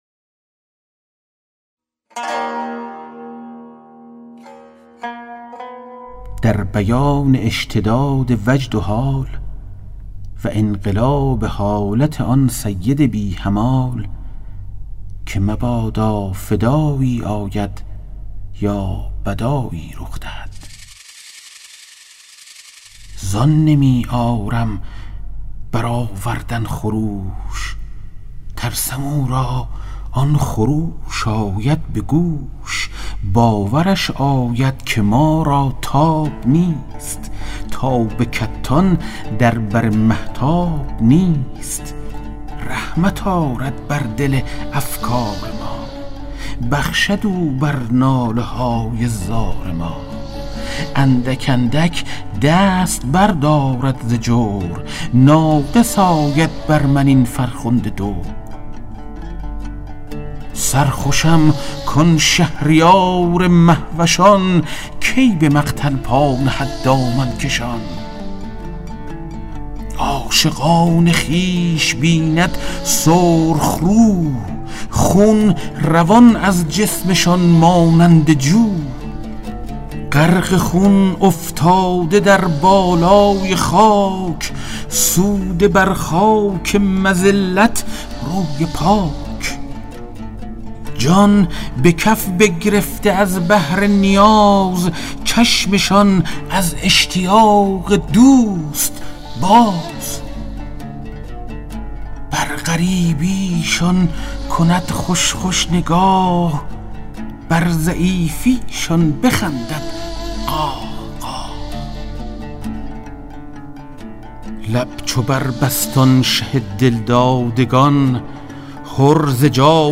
کتاب صوتی گنجینه‌الاسرار، مثنوی عرفانی و حماسی در روایت حادثه عاشورا است که برای اولین‌بار و به‌صورت کامل در بیش از 40 قطعه در فایلی صوتی در اختیار دوستداران ادبیات عاشورایی قرار گرفته است.